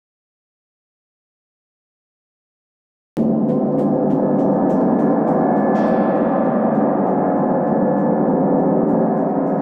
grand_stat_moy.wav